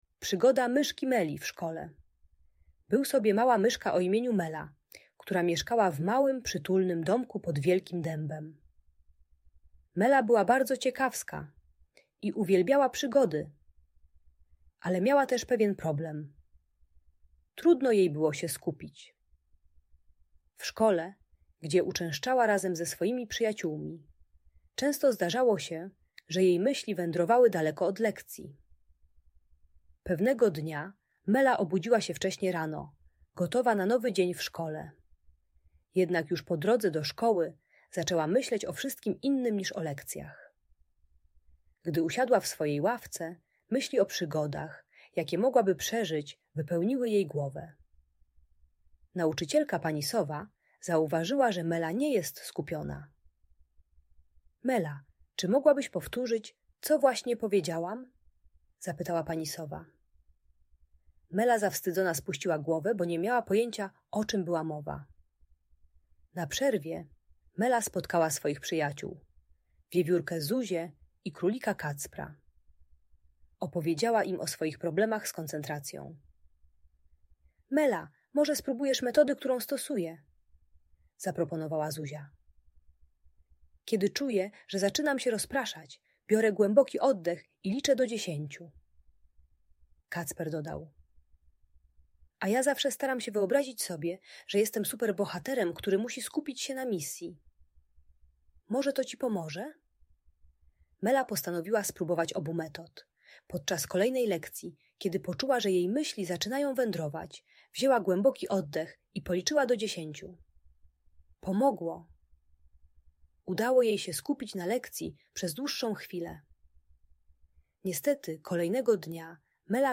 Przygoda Myszki Meli - Usypianie | Audiobajka